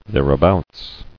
[there·a·bouts]